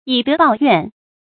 注音：ㄧˇ ㄉㄜˊ ㄅㄠˋ ㄧㄨㄢˋ
以德報怨的讀法